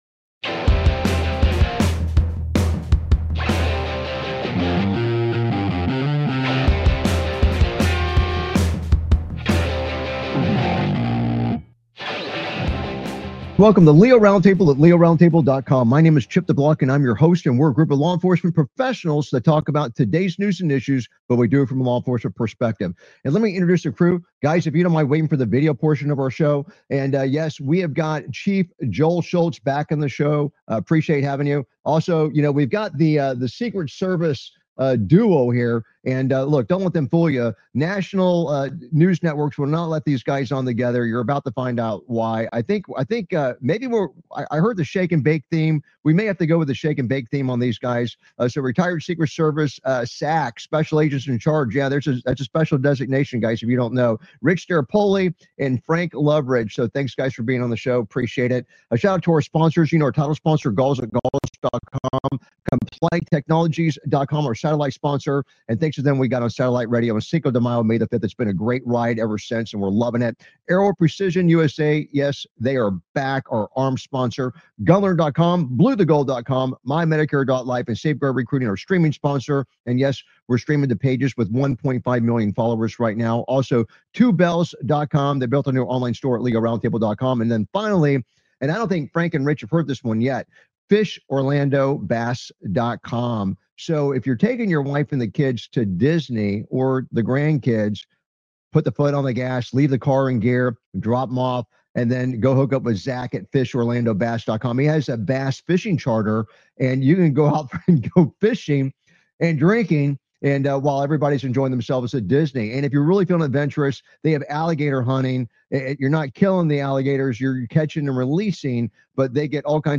Talk Show Episode, Audio Podcast, LEO Round Table and S10E169, Kash Patel's FBI Raids Ex-National Security Adviser John Bolton's Home on , show guests , about Kash Patel's FBI Raids Ex-National Security Adviser John Bolton's Home, categorized as Entertainment,Military,News,Politics & Government,National,World,Society and Culture,Technology,Theory & Conspiracy